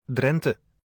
Drenthe (Dutch pronunciation: [ˈdrɛntə]